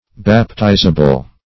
Meaning of baptizable. baptizable synonyms, pronunciation, spelling and more from Free Dictionary.
Search Result for " baptizable" : The Collaborative International Dictionary of English v.0.48: Baptizable \Bap*tiz"a*ble\, a. Capable of being baptized; fit to be baptized.